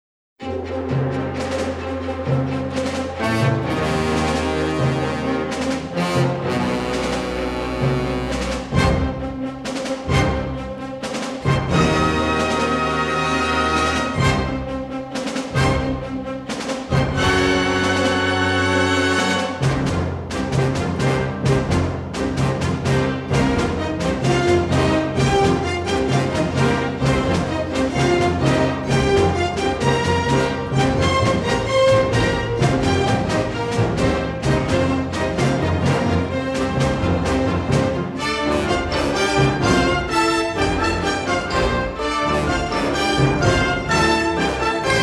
each filled with exotic melody and instrumentation.
remixed in stereo from the original three-track masters.